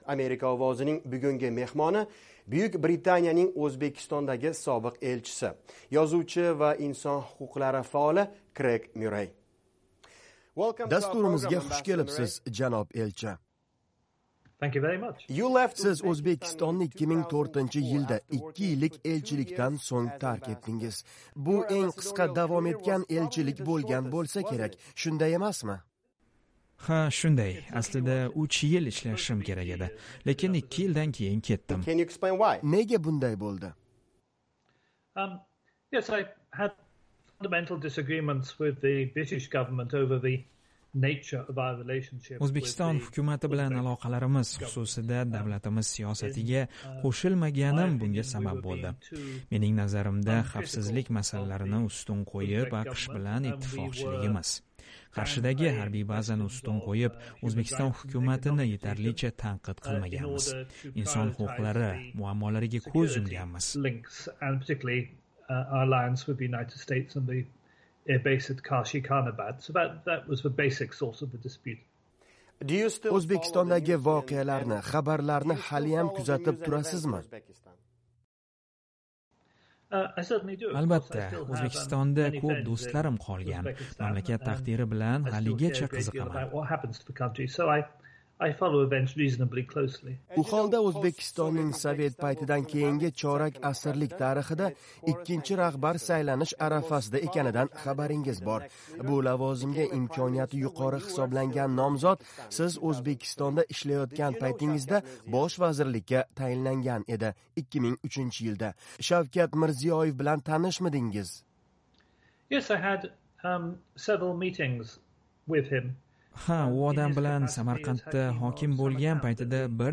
Buyuk Britaniyaning O‘zbekistondagi sobiq elchisi, yozuvchi va inson huquqlari faoli Kreyg Myurrey (Craig Murray) yaqinda "Amerika Ovozi" bilan skayp orqali suhbat davomida O‘zbekistonda kechgan yillarini esladi, bo‘lajak saylovlar va Shavkat Mirziyoev haqidagi fikrlari bilan o‘rtoqlashdi.